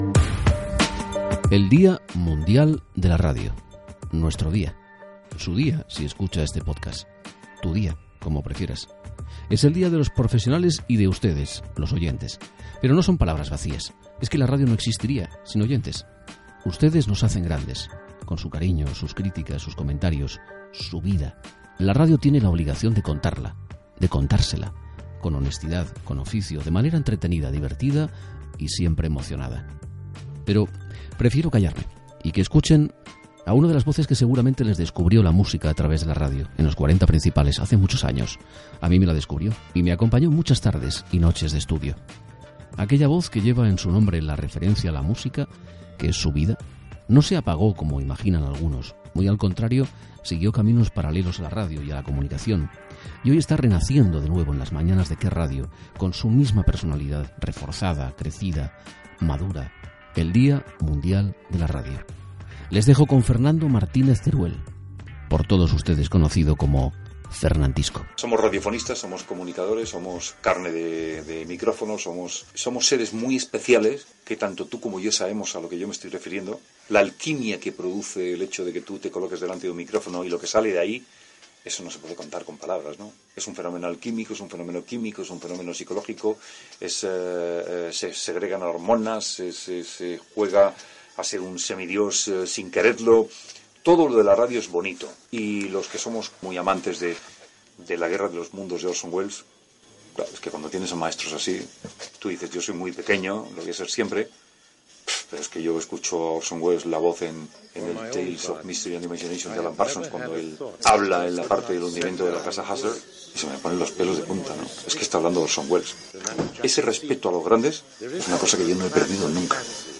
Las voces de Encarna Sánchez, Antonio Herrero, Joaquín Prat, Luis del Olmo y Carlos Herrera forman parte de este especial por el Día Mundial de la Radio
Como puede escucharse en este audio, el equipo de archivo de la cadena recupera por etapas las primeras palabras de estas estrellas presentándose ante sus oyentes.
También podrás escuchar el inicio de la nueva temporada de COPE en 1992 con la inconfundible voz deAntonio Herrero, histórico conductor de «La mañana».
dia_mundial_radio.mp3